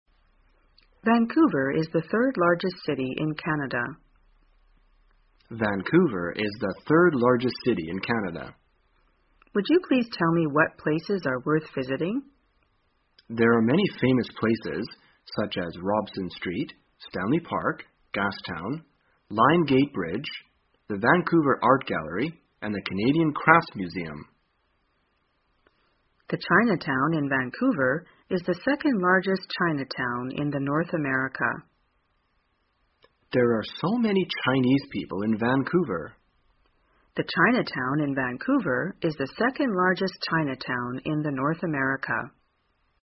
在线英语听力室生活口语天天说 第360期:怎样谈论温哥华的听力文件下载,《生活口语天天说》栏目将日常生活中最常用到的口语句型进行收集和重点讲解。真人发音配字幕帮助英语爱好者们练习听力并进行口语跟读。